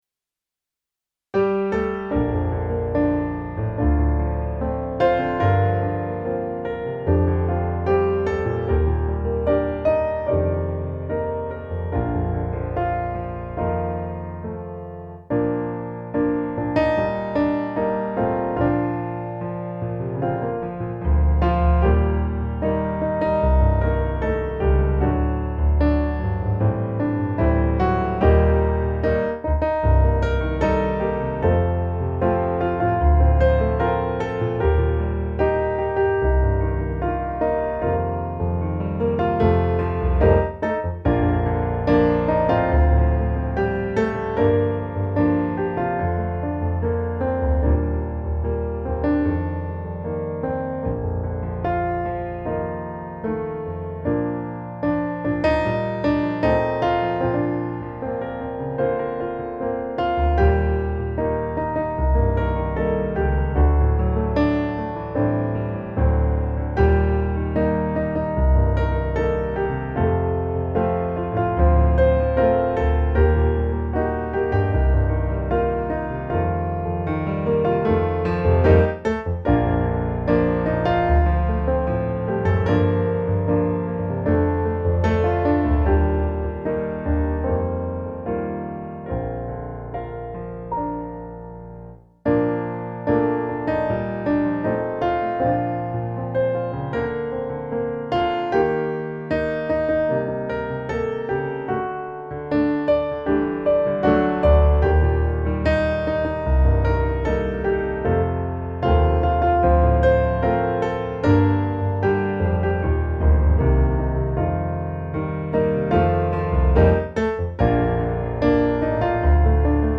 Musikbakgrund Psalm